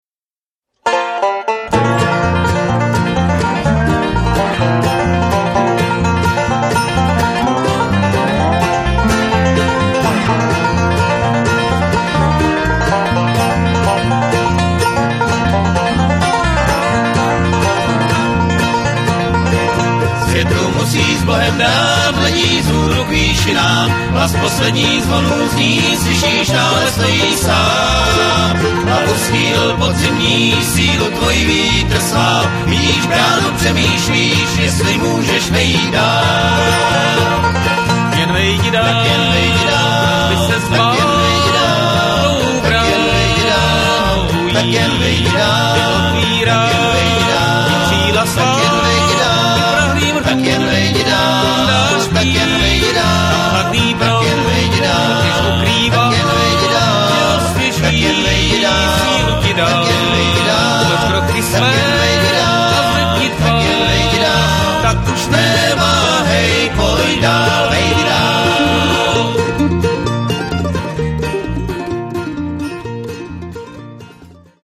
guitar, vocal
dobro, vocal